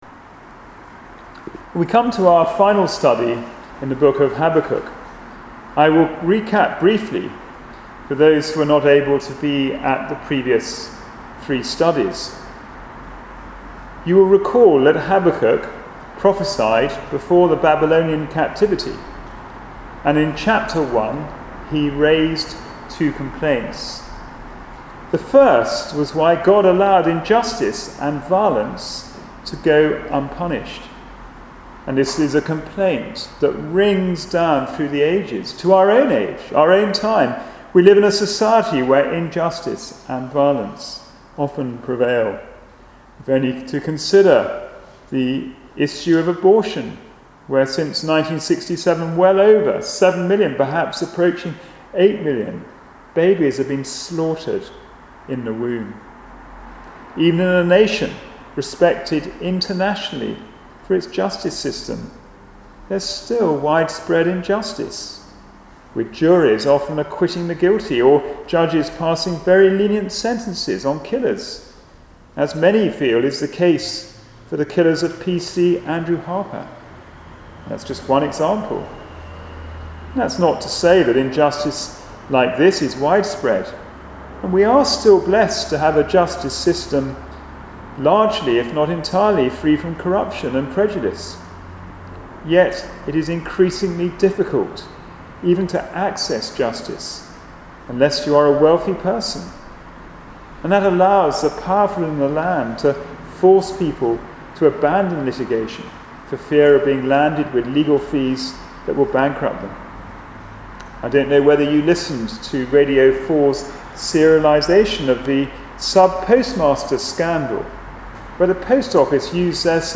Habakkuk Passage: Habakkuk 3 Service Type: Sunday Morning Service « Jesus Christ